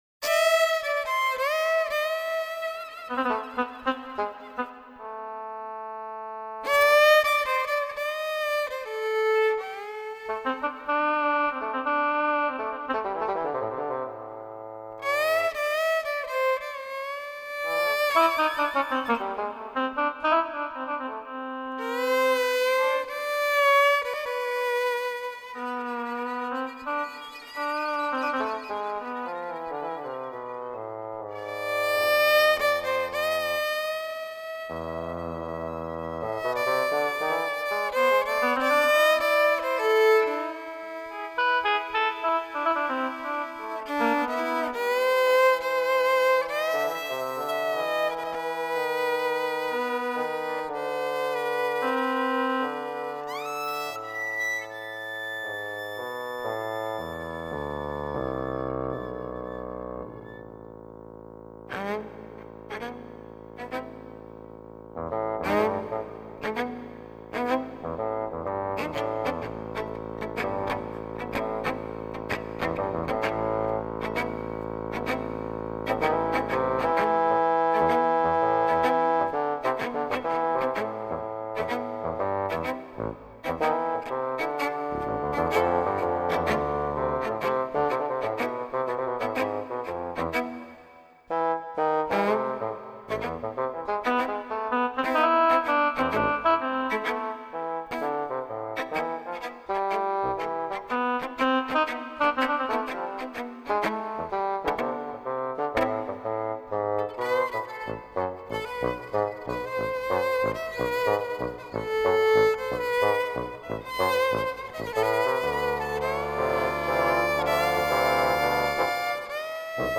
recorded in Shutesbury Mass.
Piano
Bass